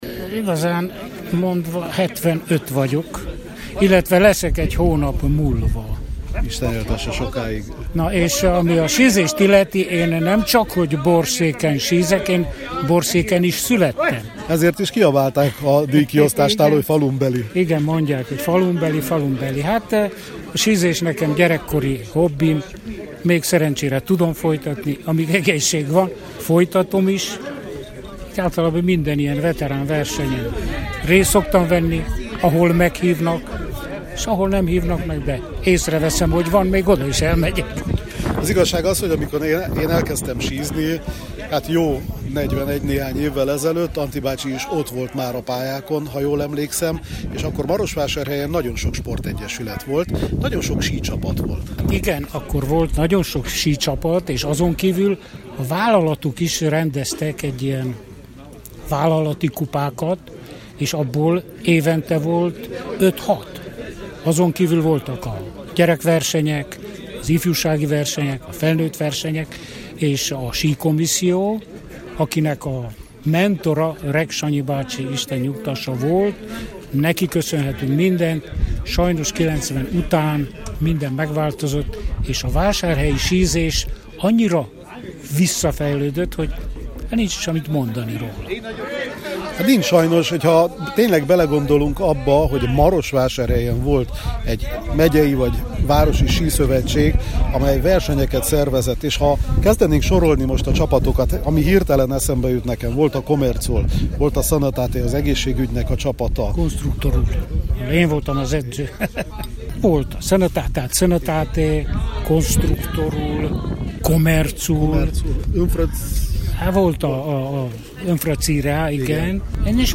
A Kispadon vele elevenítettük fel a régi emlékeket: